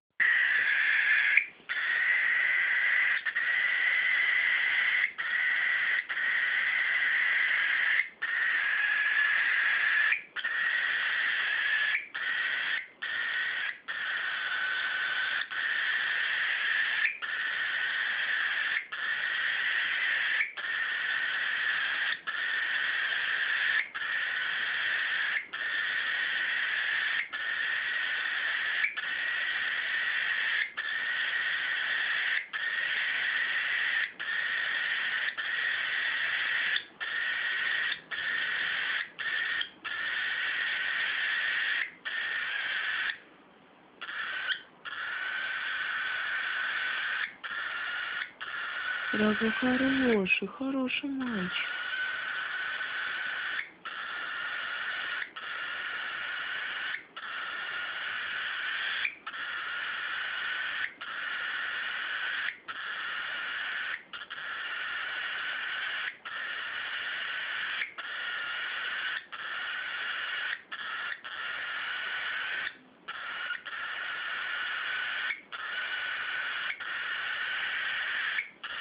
Sounds of a Corella, a parrot chirping, download and listen online
• Category: Corella
On this page you can listen to sounds of a corella, a parrot chirping, download and listen online.